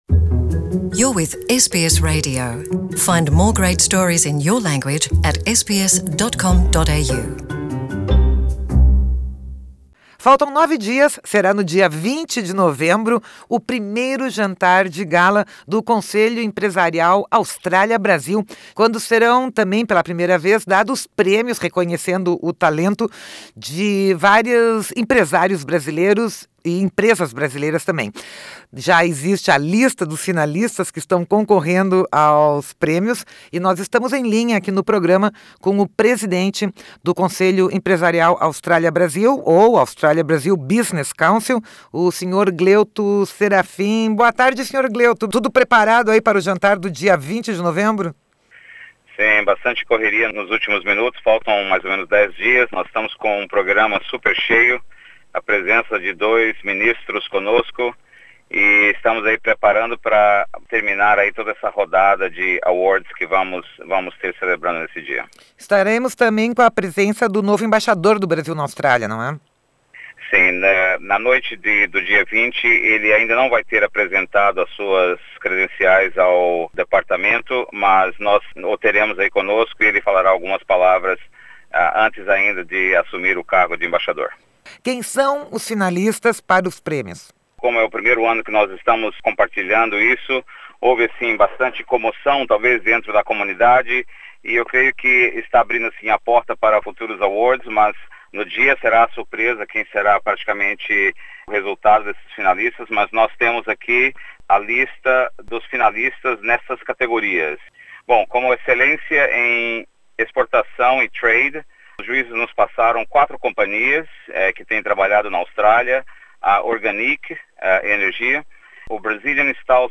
faz o anúncio dos finalistas nesta entrevista ao Programa de Língua Portuguesa da Rádio SBS.